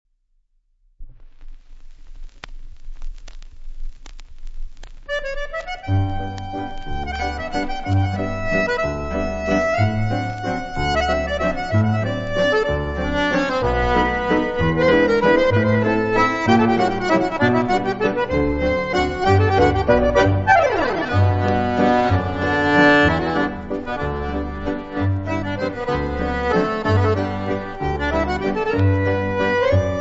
fisarmonica